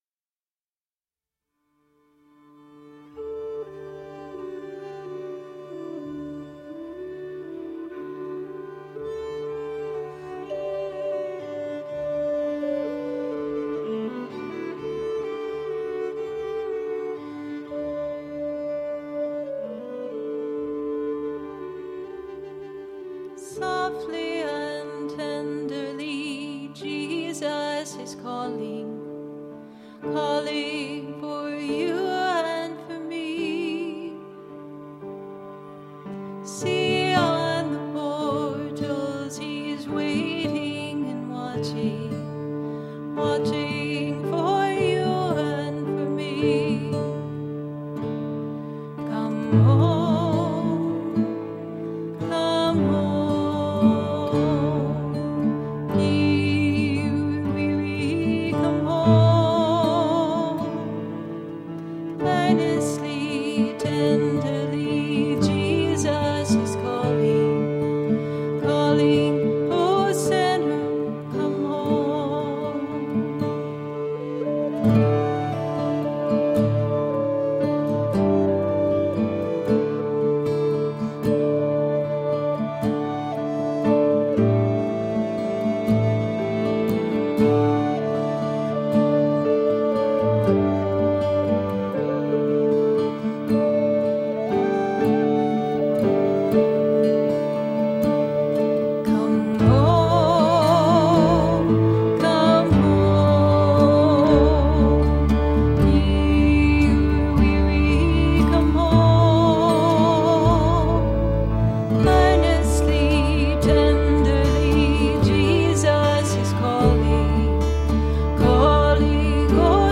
Intriguing piano compositions with dynamic accompaniment.
Tagged as: New Age, Folk